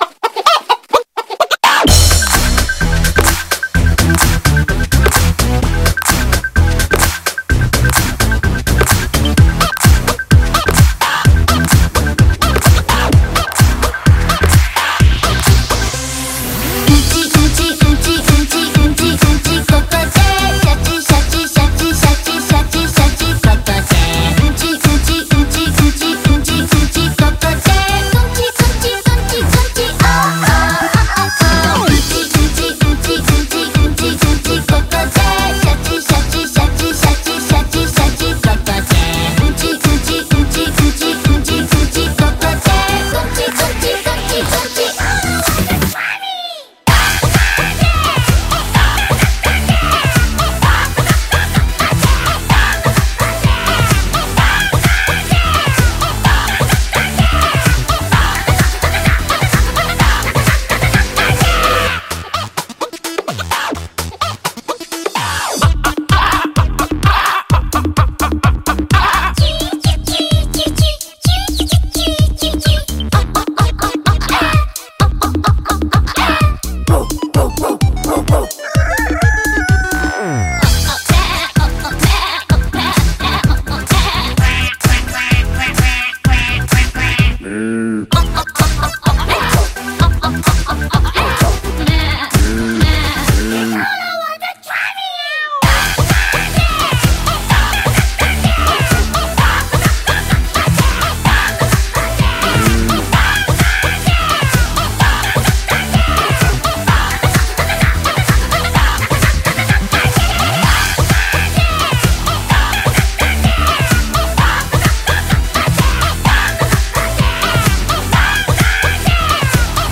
BPM128
Audio QualityCut From Video